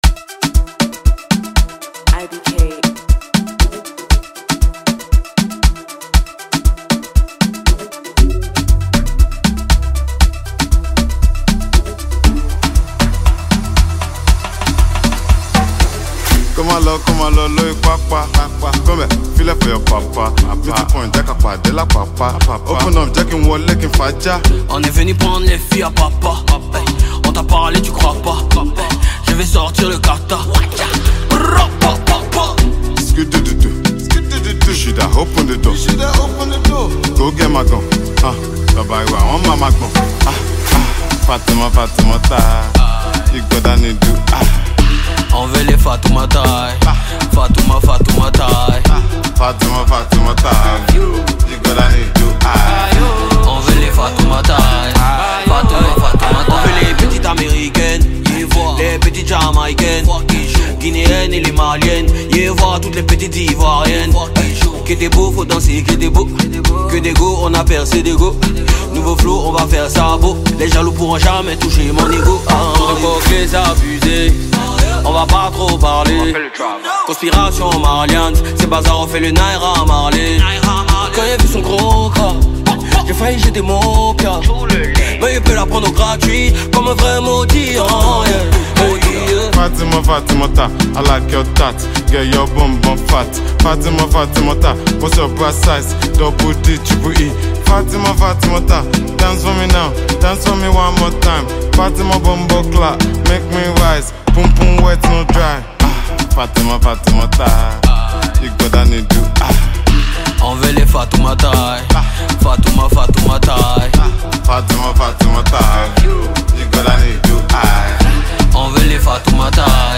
captivating rhythms and lyrical prowess
Afrobeats